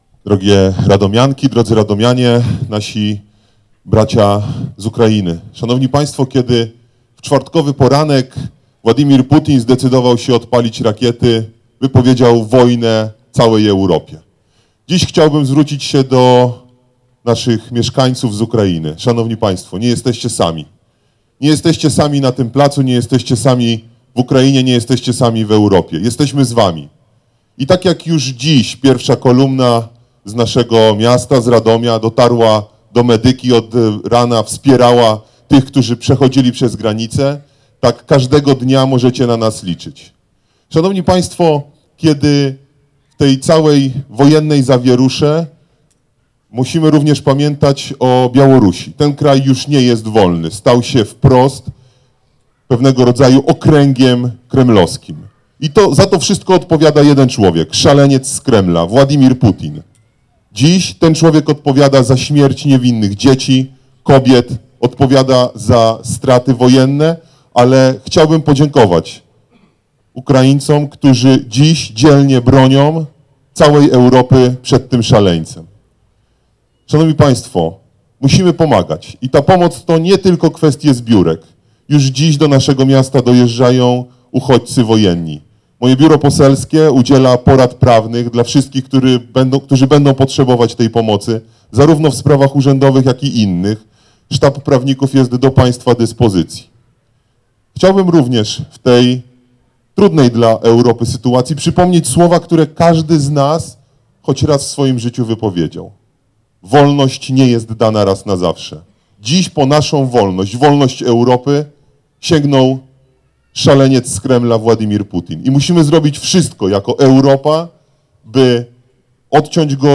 Radomianie dla Demokracji i radomska Platforma Obywatelska zorganizowali Wiec Solidarności z Ukrainą.
Do zdecydowanych działań i konkretów namawiał poseł Konrad Frysztak: